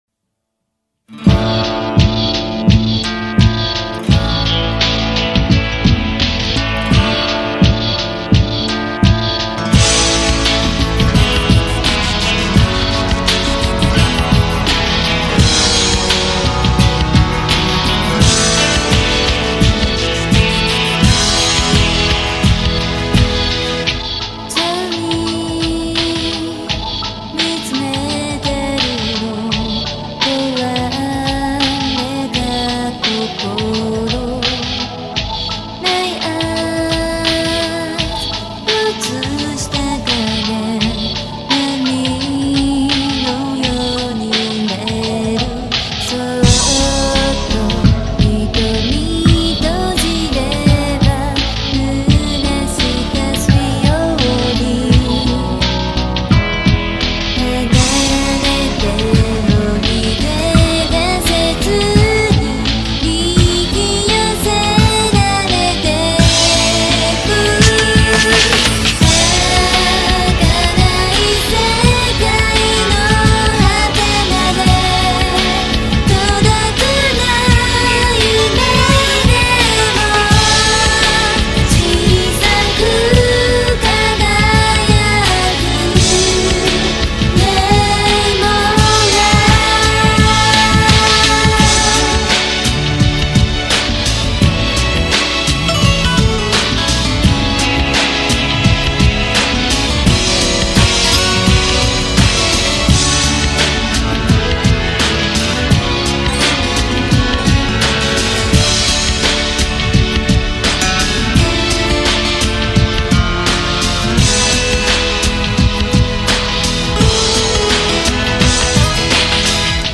Ending theme